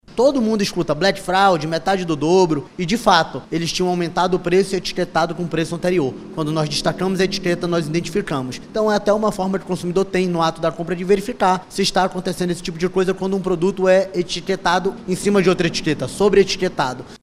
O diretor-presidente do órgão, Jalil Fraxe, traz um exemplo do que aconteceu no último ano.
SONORA01_JALIL-FRAXE.mp3